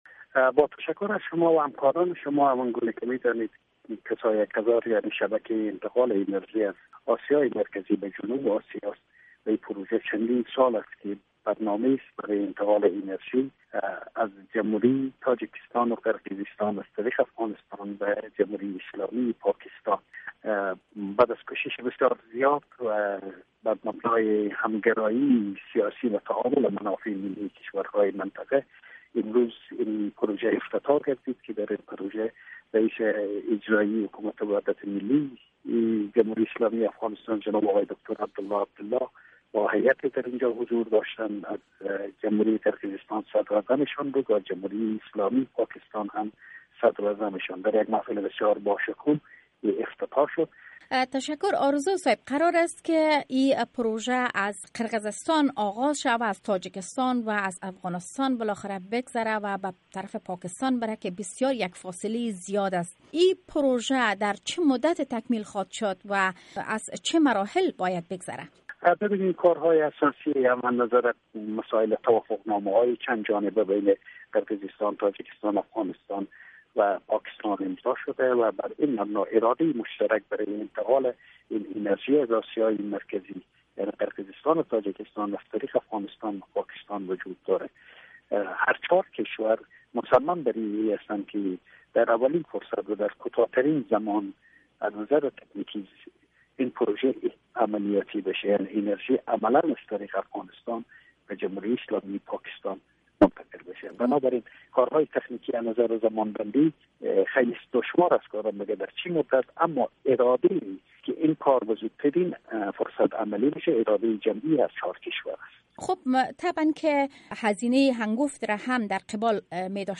جریان مکمل صحبت با سفیر افغانستان در تاجکستان را از اینجا شنیده می توانید